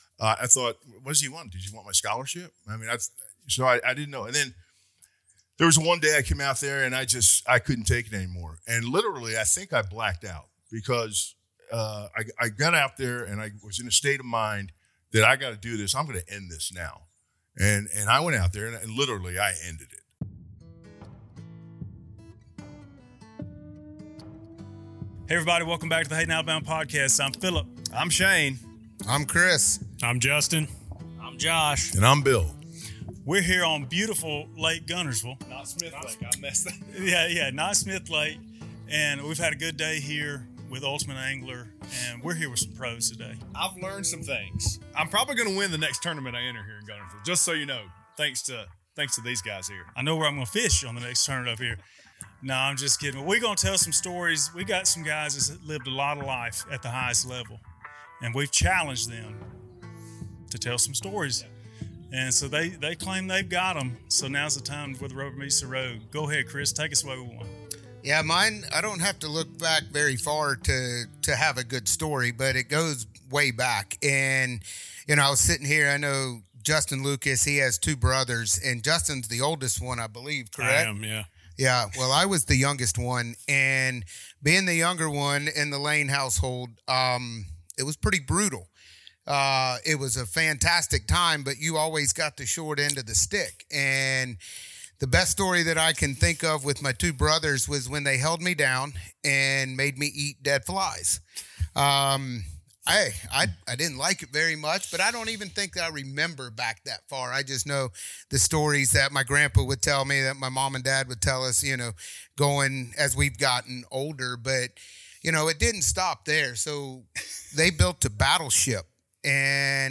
Their stories did not disappoint and had us laughing the whole time.